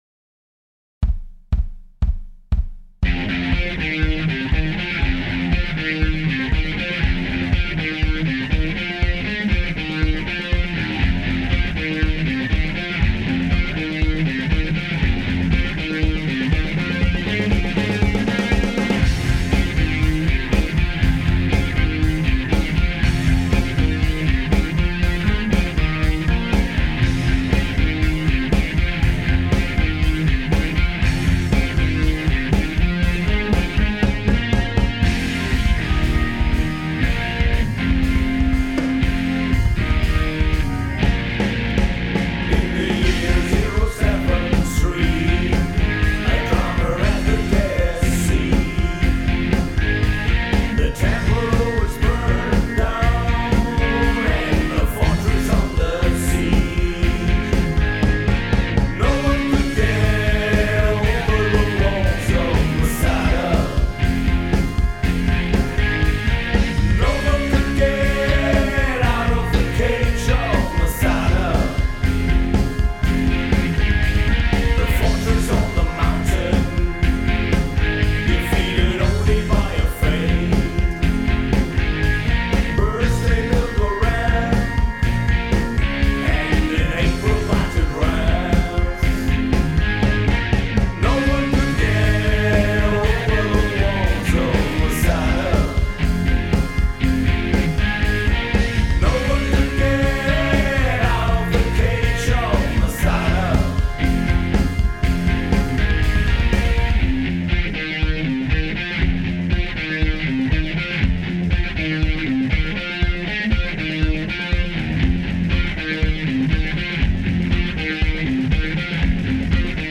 Rock
Dies ist eine komplette Überarbeitung mit neuem Text und Arrangement.